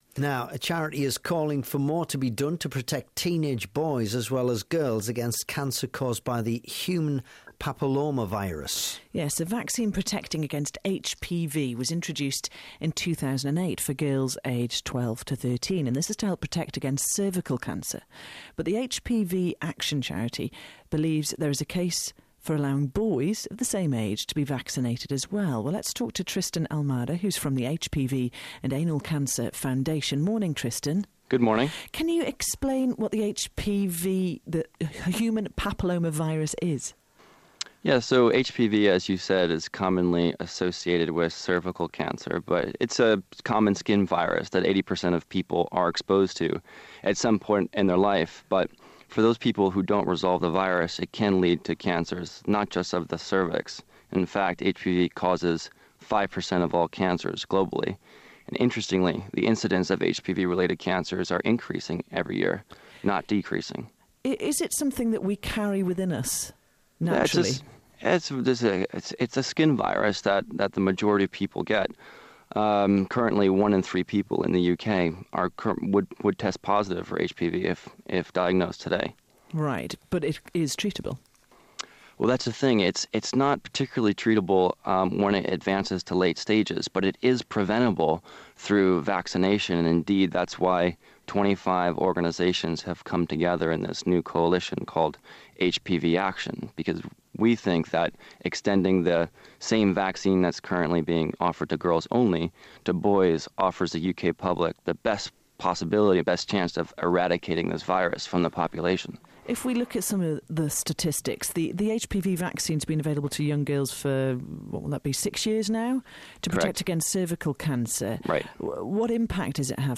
interview on BBC Newcastle